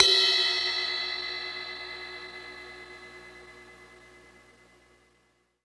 Closed Hats
Ride_8.wav